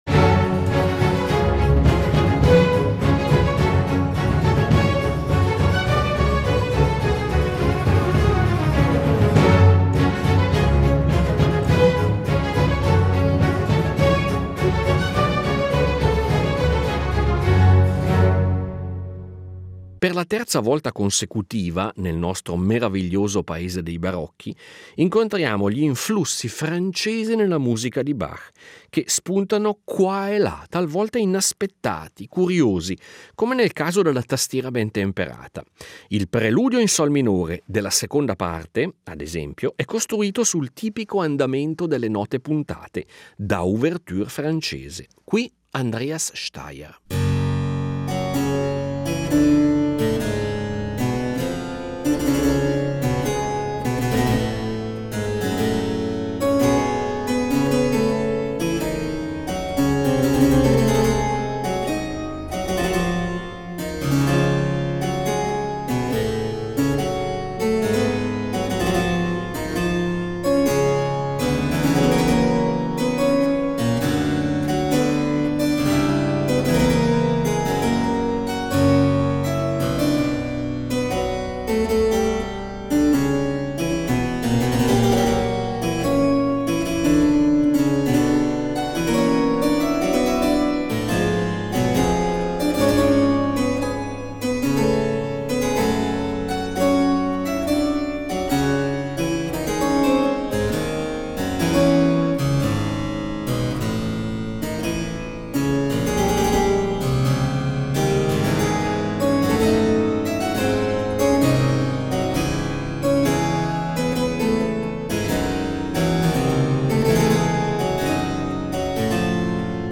Curiosando tra le numerose composizioni del sommo Johann Sebastian, rintracciamo numerose composizioni impregnate dello stile francese. In tre puntate del nostro meraviglioso “Paese dei Barocchi” ascoltiamo estratti da trascrizioni di Couperin, dall’Arte della Fuga, da Suites clavicembalistiche, brani per organo, Suites per orchestra, Variazioni Goldberg e Concerti brandeburghesi, tutti dotati del tipico condimento francese.